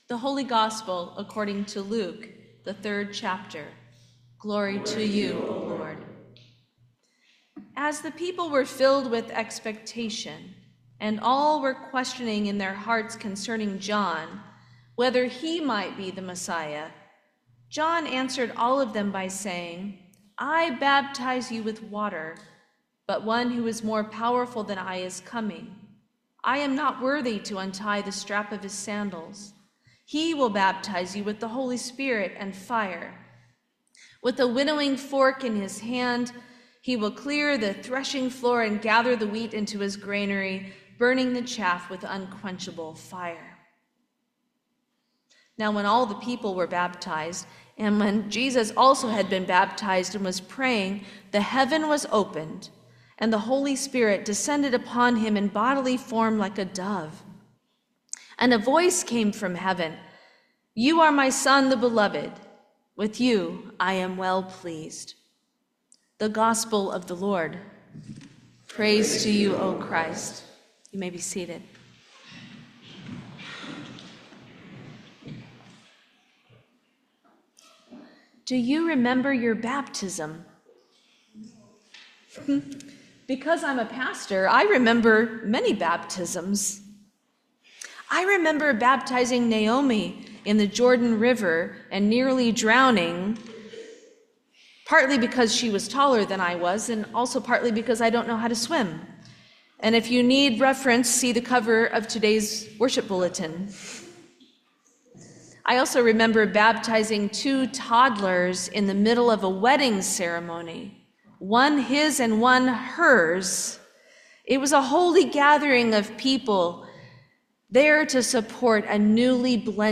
Sermon for the Baptism of Our Lord 2025